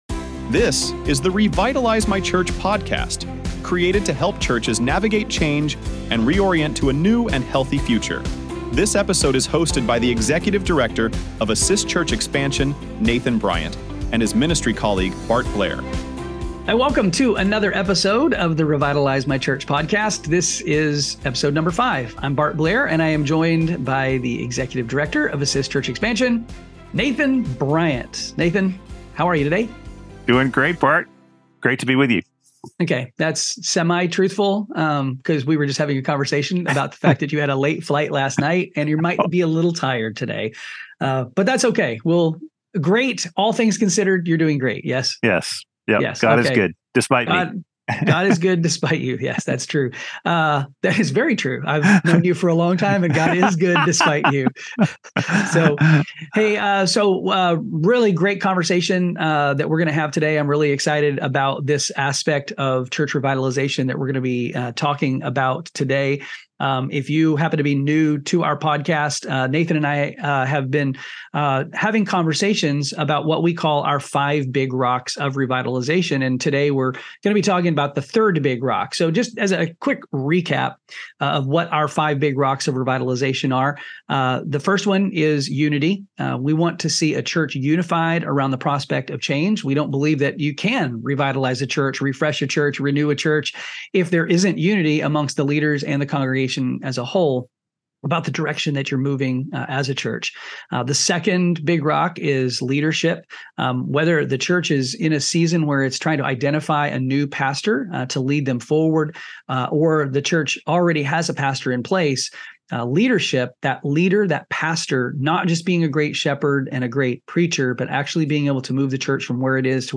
This topic is the third of their "five big rocks" of church revitalization, following unity and leadership. The conversation begins by emphasizing the biblical foundation for team ministry.